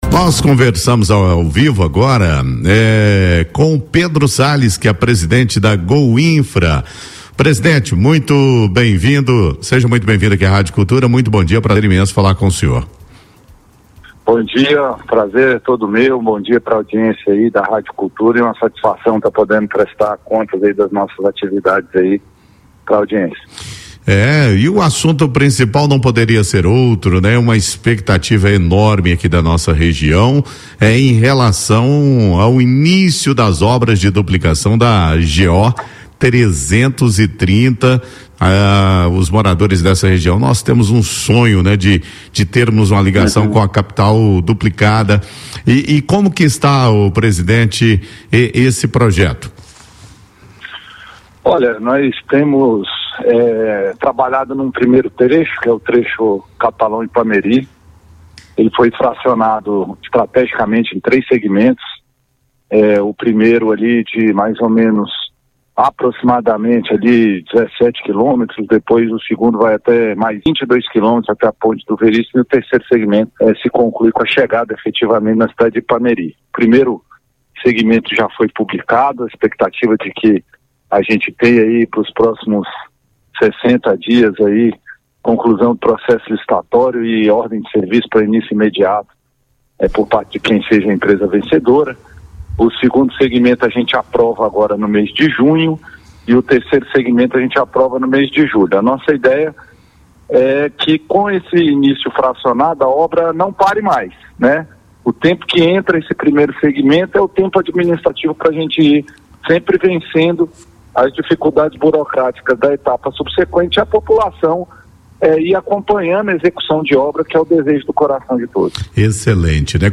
Durante entrevista ao programa Cultura em Foco, da Rádio Cultura FM 101,1 o presidente da Goinfra, Pedro Sales, afirmou que as obras de duplicação da GO-330 entre Catalão e Ipameri vão começar nas próximas semanas.